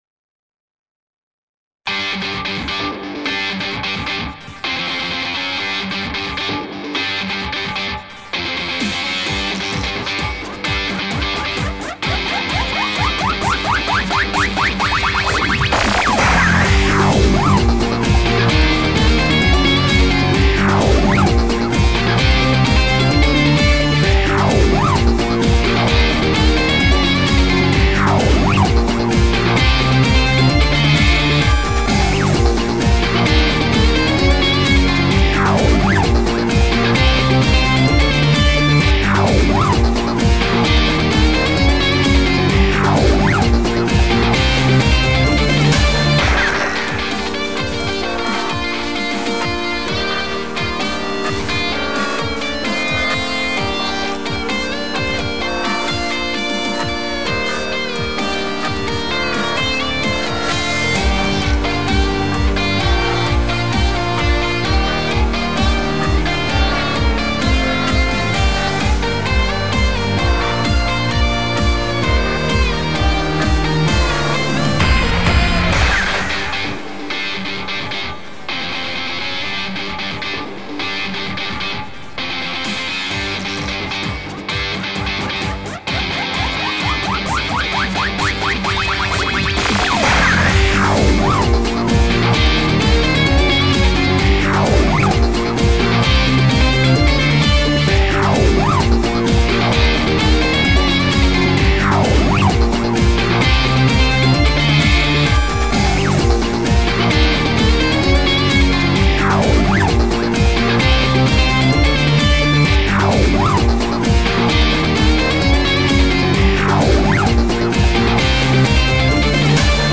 一応ループする曲です（この音源では三周します） まだまだMIXとか色々未熟なんですが、これから頑張っていこうと思ってるのですー